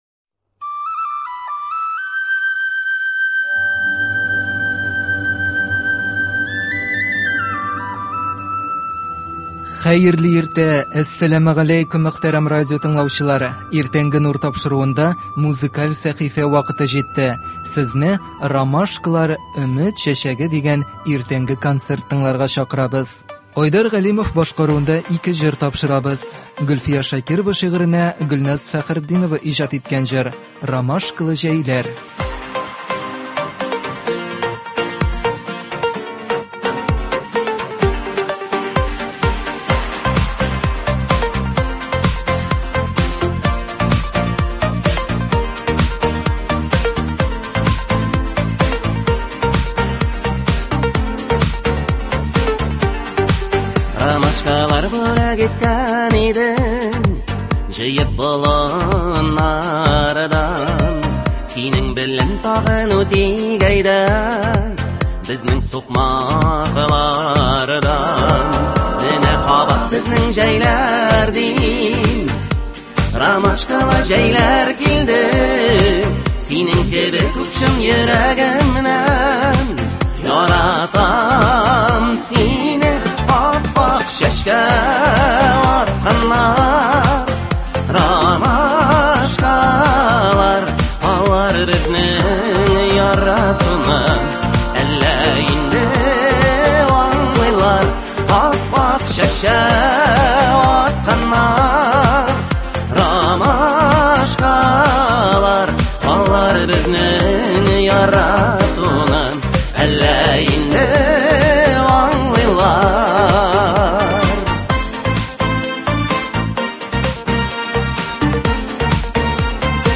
Бүген сезне “Ромашкалар – өмет чәчәге” дигән иртәнге концерт тыңларга чакырабыз.